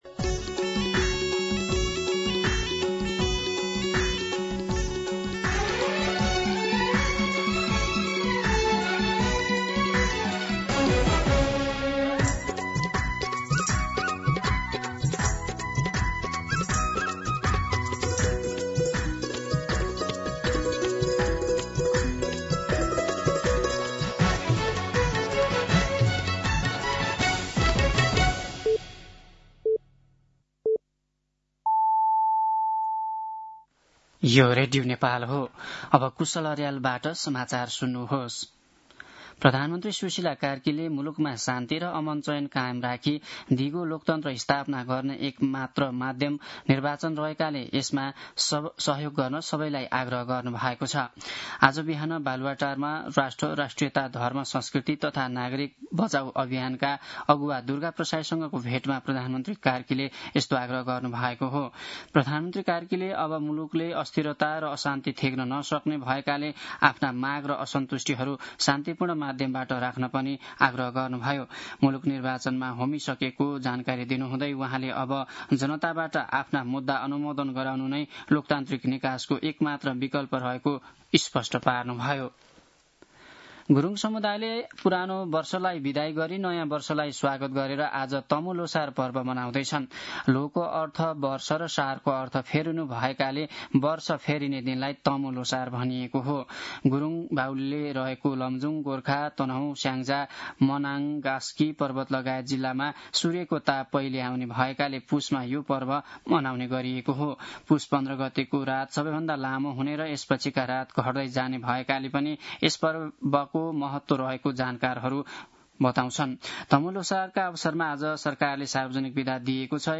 मध्यान्ह १२ बजेको नेपाली समाचार : १५ पुष , २०८२
12pm-News-15.mp3